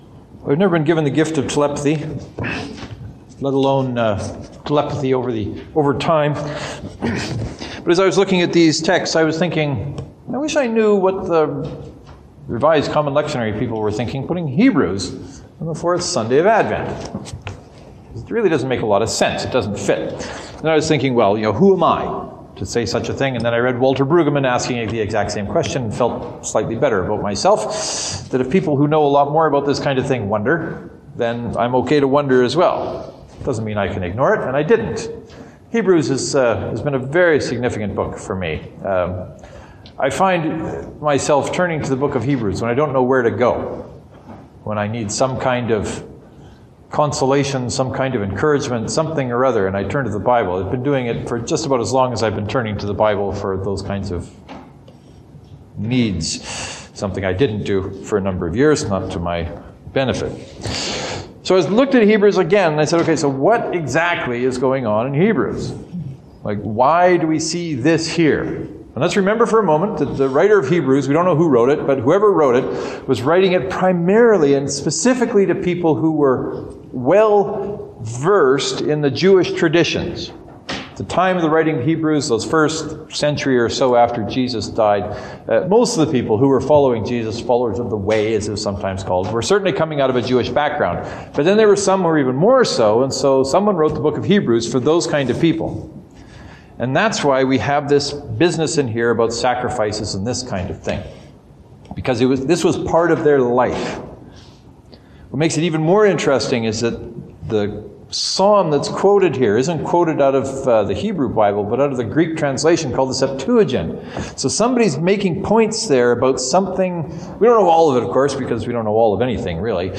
I’ll get to this Sunday’s sermons in a moment or two but first there are a few words I need to say.